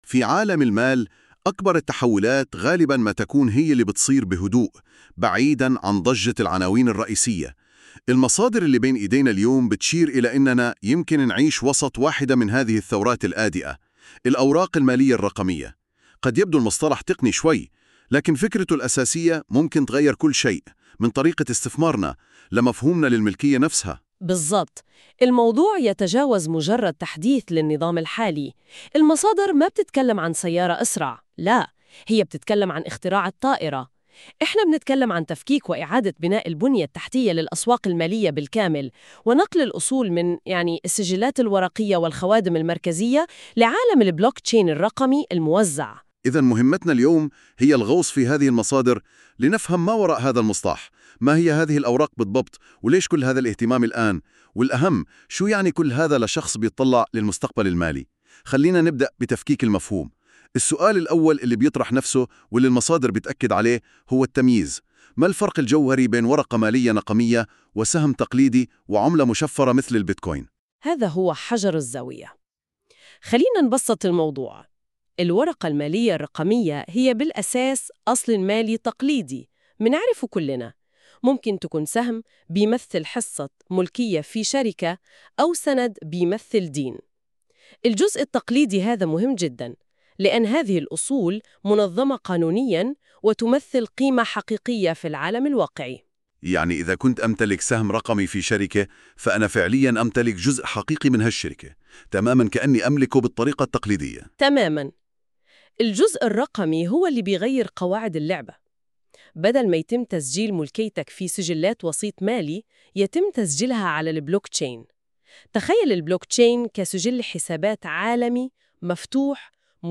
يمكنك الاستماع إلى هذا المقال بدلاً من قراءته عبر المقطع الصوتي التالي👇